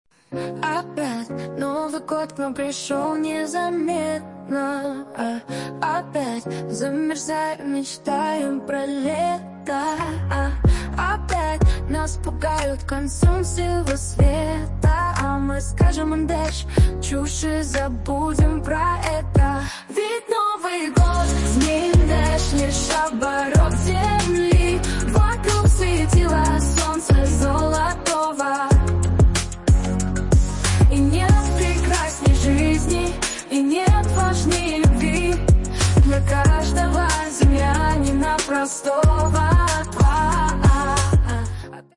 Фрагмент варианта исполнения: